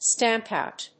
stámp óut
発音